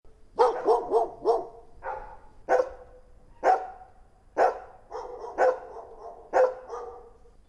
Dog Barking In Distance Sound Button - Free Download & Play